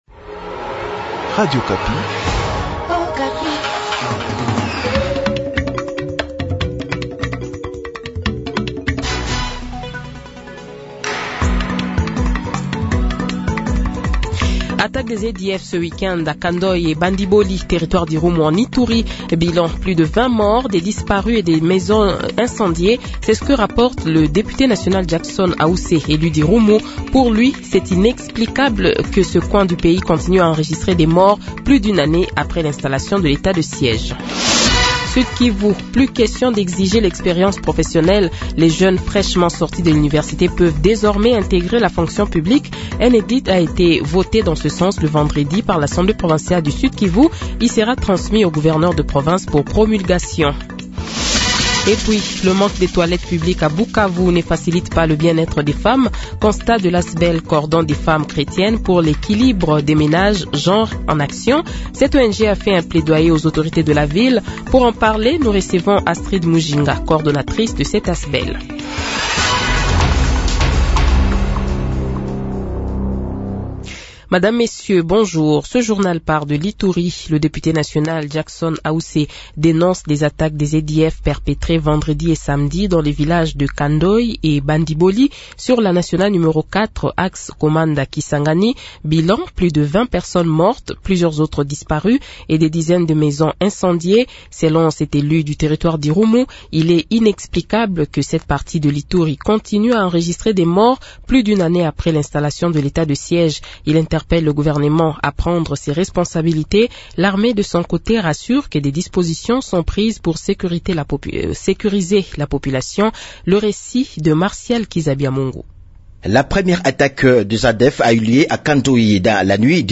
Journal Midi
Le Journal de 12h, 07 Aout 2022 :